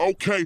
BWB VOX (OK KANYE).wav